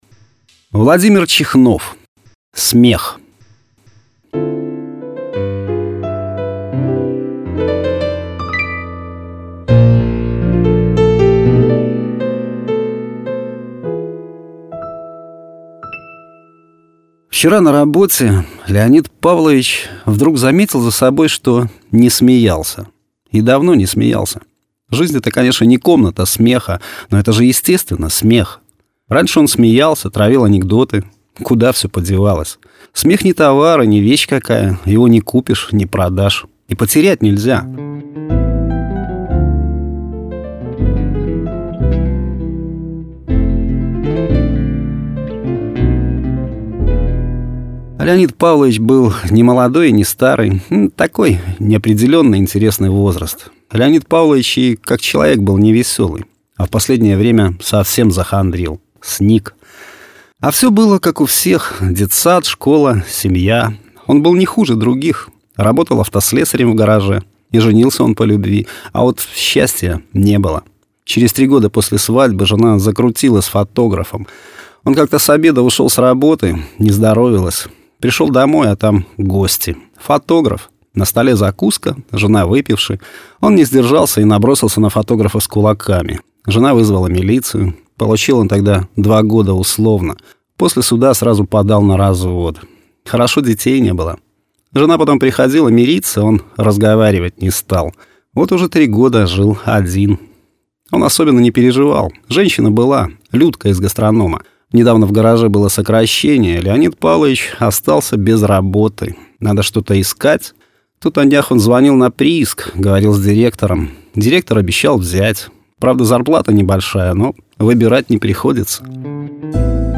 Аудио-Рассказы
Жанр: Современная короткая проза
Качество: mp3, 256 kbps, 44100 kHz, Stereo